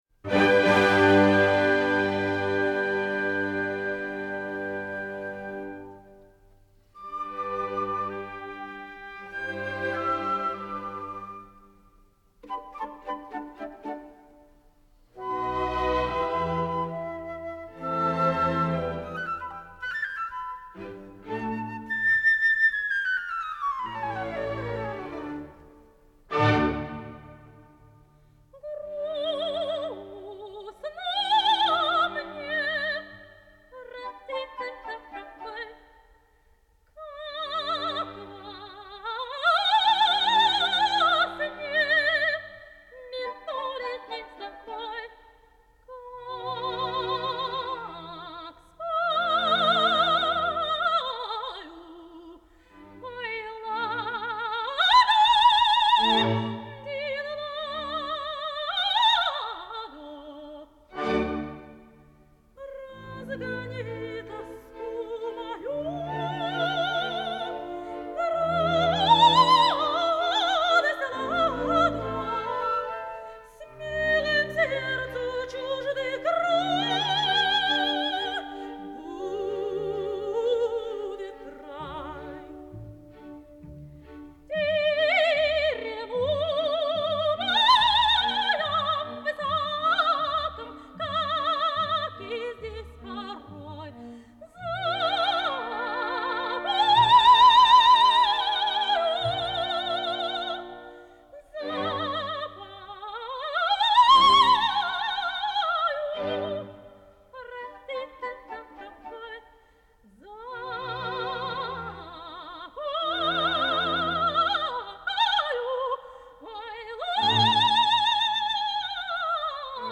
Работала в Киевском театре оперы и балета (1956-1973), Большом театре (Москва).
Народная артистка СССР (1960).